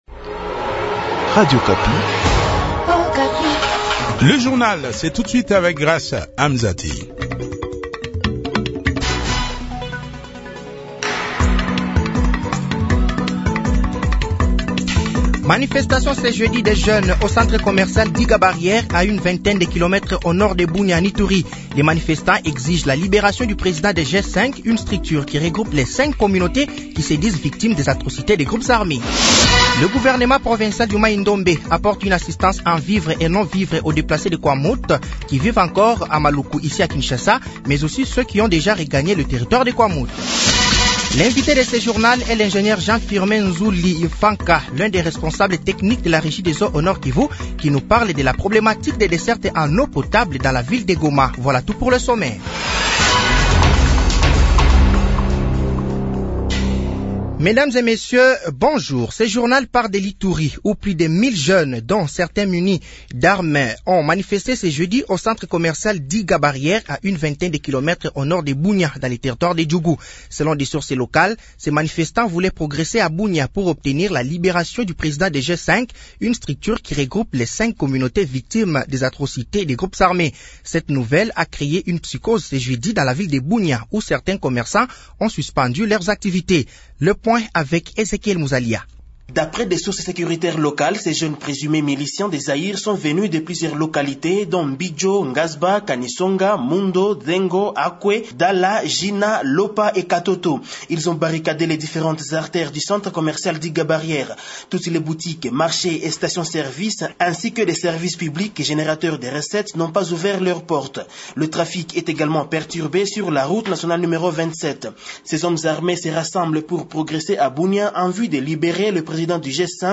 Journal français de 12h de ce jeudi 10 août 2023